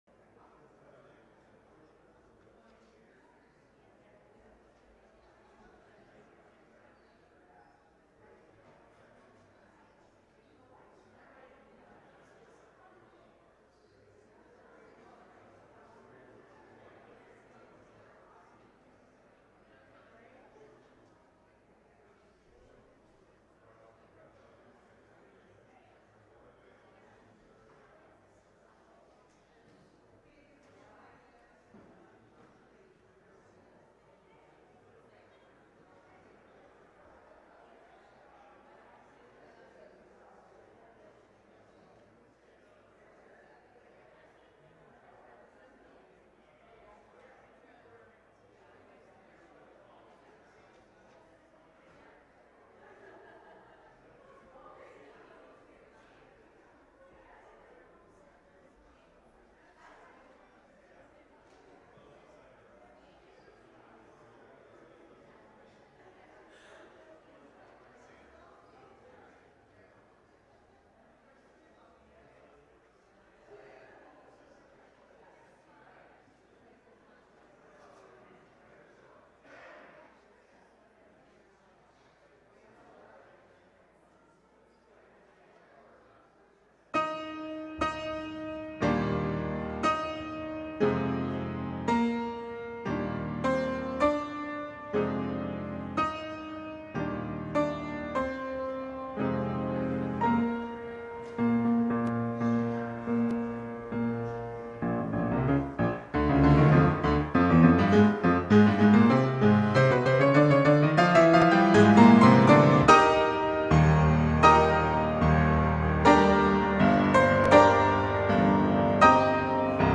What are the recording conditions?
October 31, 2021 (Morning Worship)